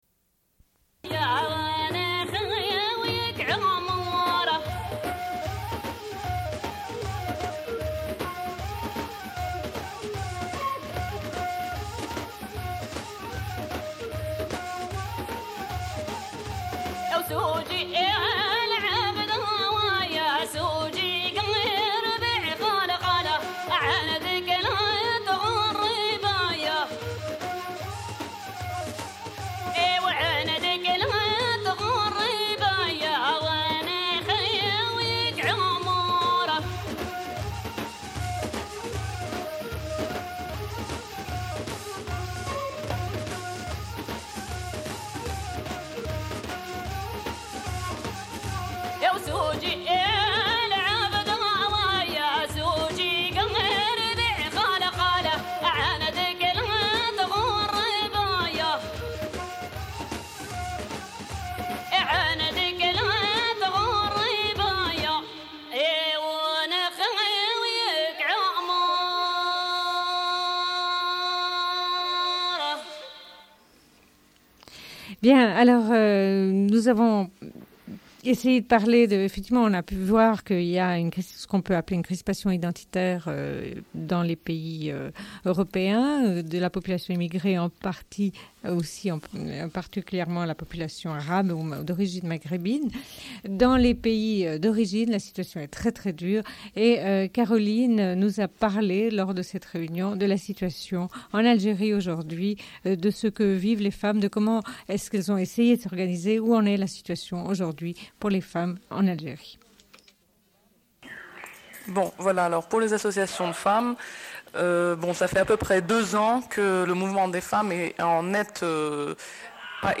Une cassette audio, face A31:39
Radio Enregistrement sonore